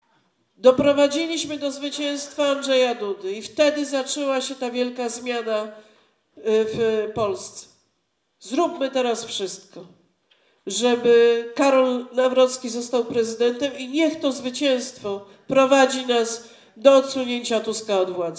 Do głosowania na Karola Nawrockiego zachęcała w sobotę w Suwałkach Beata Szydło, euoposłanka i była premier RP. Razem z posłami Prawa i Sprawiedliwości przekonywała zgromadzonych w Parku Naukowo-Technologicznym, aby w II turze wyborów poparli kandydata wspieranego przez PiS. Zapewniała, że Karol Nawrocki dotrzymuje słowa.